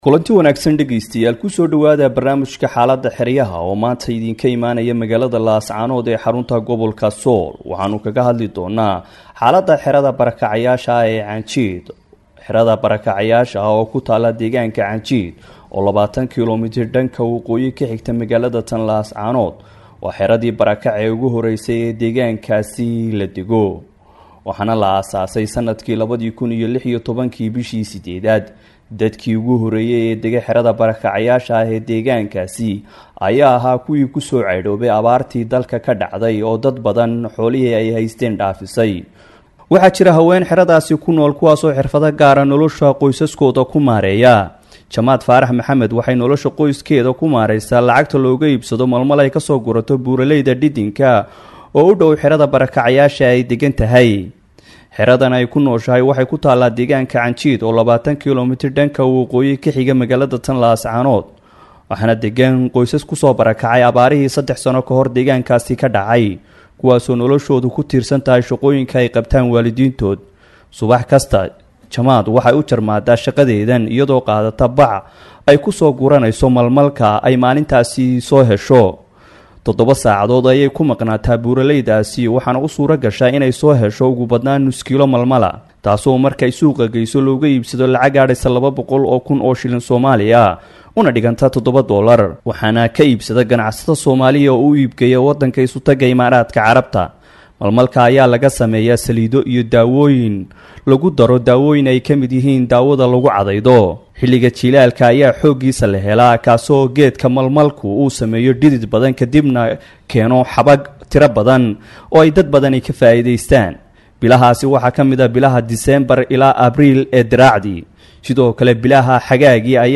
Weriyeyaasheenna ayaa booqda xeryaha kaddibna barnaamij dhinacyo kala duwan ah ka soo diyaariya, iyagoo ku saleynaya hadba waxa markaas xiisaha leh ee ay la soo kulmaan.
warbixin